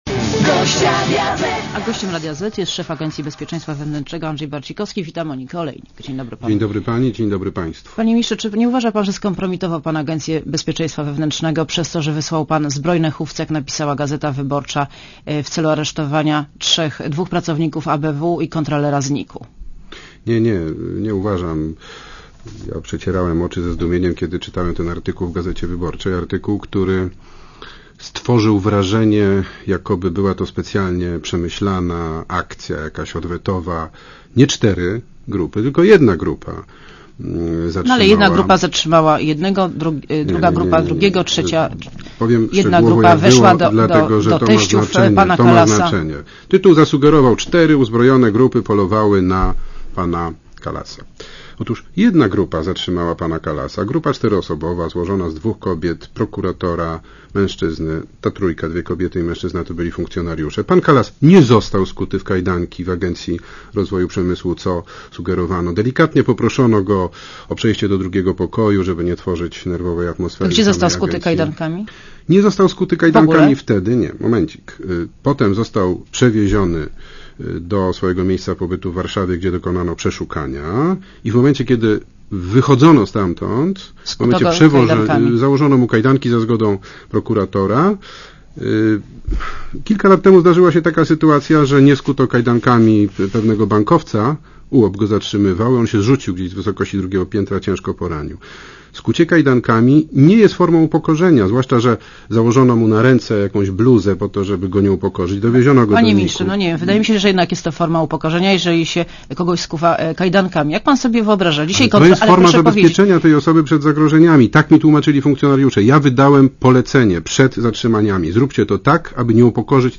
Rozmowa z Andrzejem Barcikowskim